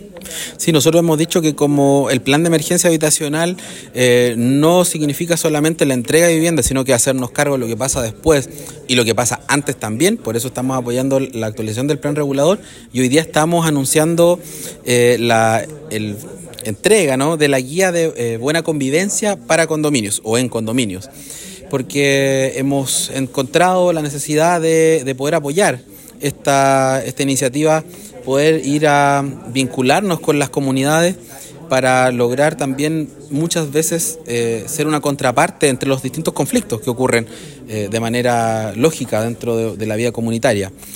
Al respecto, el Seremi de Vivienda y Urbanismo Fabián Nail expresó que  desde la publicación de la Ley de Copropiedad Inmobiliaria,  se ha buscado que haya más educación sobre esta materia, más preparación para enfrentar los conflictos y para hacer oportunamente los arreglos en cuestiones que a veces resultan básicas.